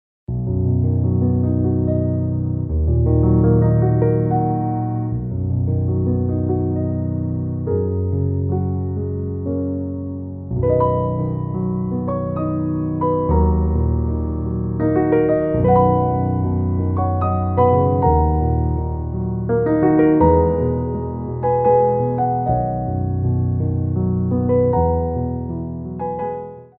Piano Arrangements of Popular Music
3/4 (16x8)